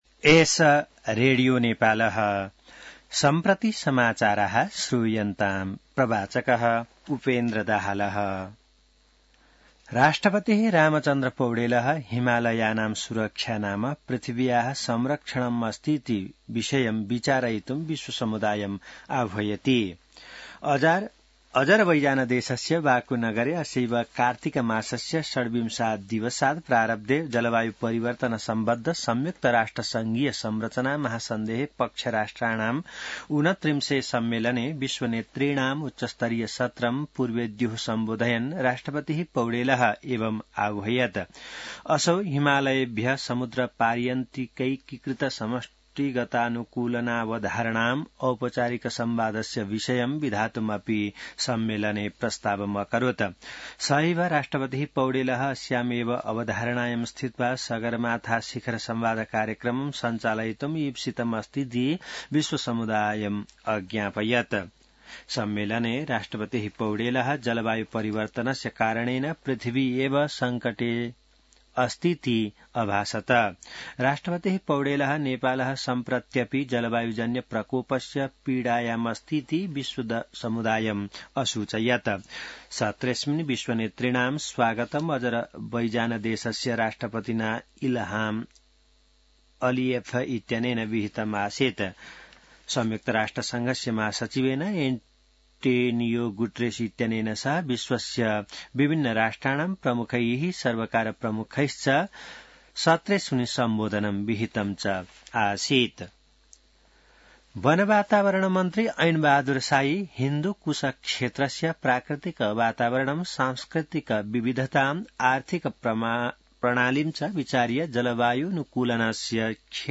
An online outlet of Nepal's national radio broadcaster
संस्कृत समाचार : २९ कार्तिक , २०८१